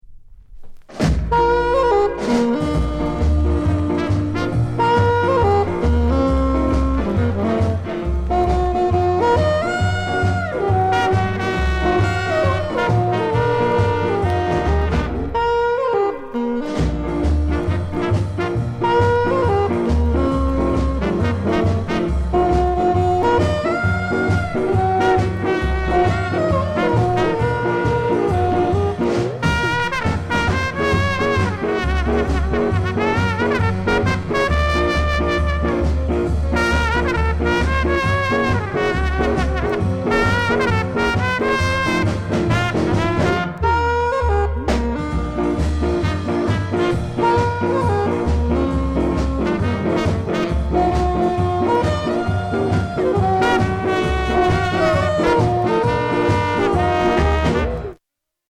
GREAT SKA INST